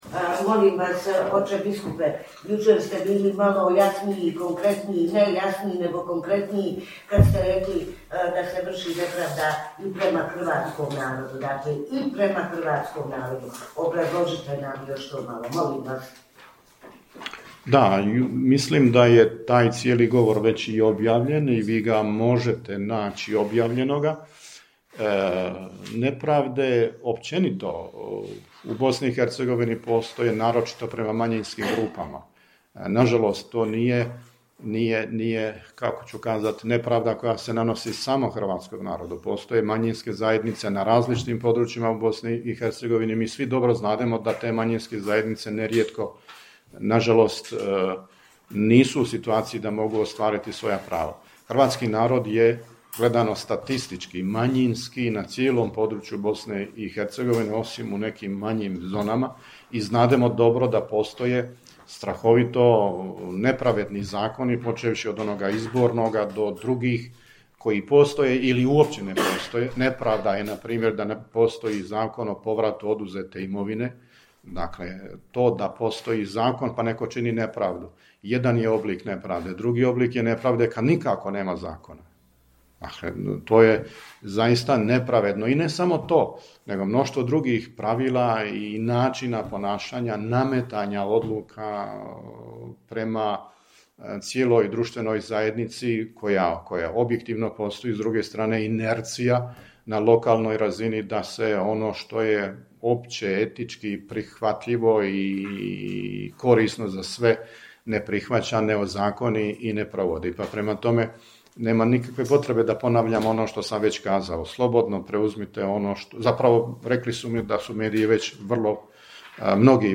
VIDEO: Održana konferencija za medije na kraju 94. redovitog zasjedanja BK BiH u Sarajevu